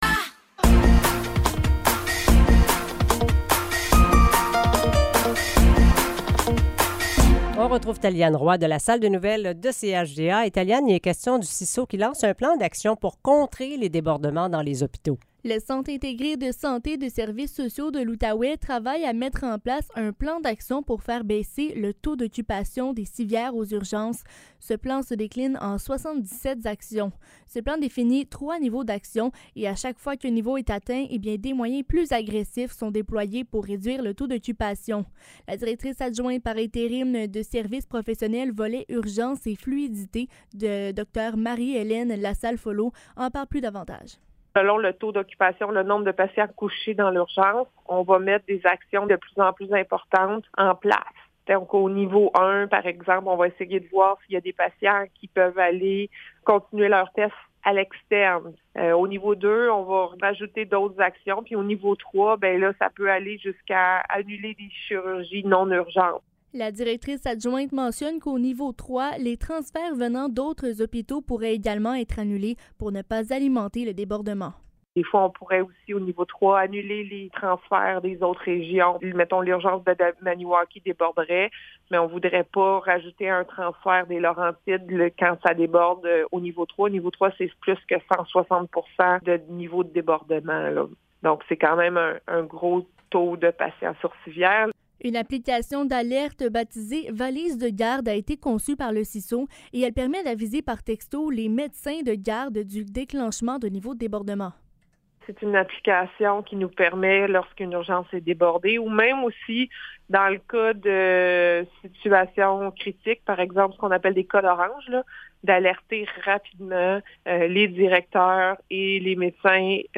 Nouvelles locales - 14 juillet 2023 - 15 h